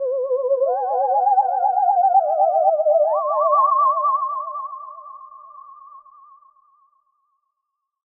Blastwave_FX_GhostHover_SFXB.355
Tags: ghost